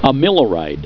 Pronunciation
(a MIL oh ride)